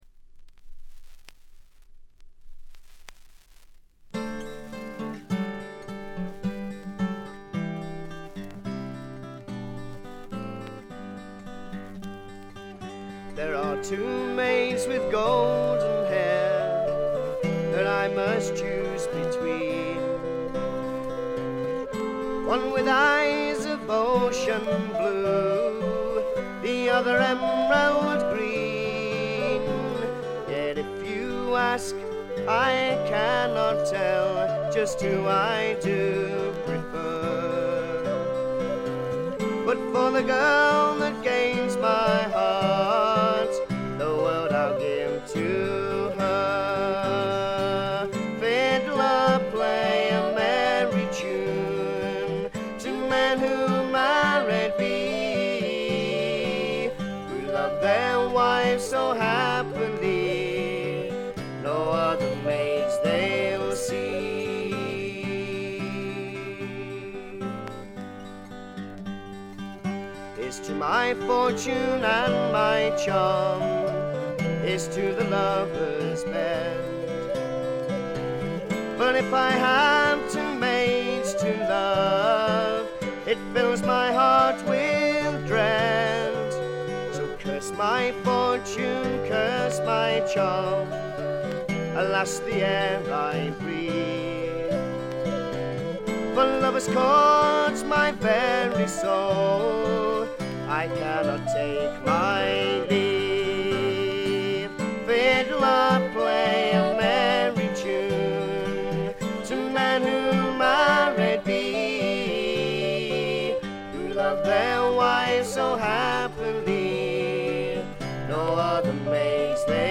バックグラウンドノイズ、チリプチ多め大きめ。プツ音少々。
すべて自作の曲をまるでトラッドのように演奏しています。
試聴曲は現品からの取り込み音源です。